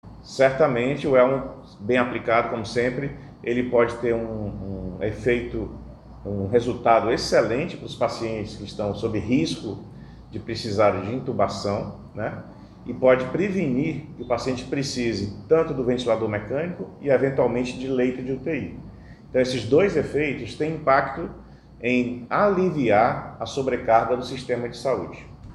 Marcelo Alcantara afirma que o capacete pode ser um importante aliado na recuperação de pacientes e no sistema público de saúde do Amazonas.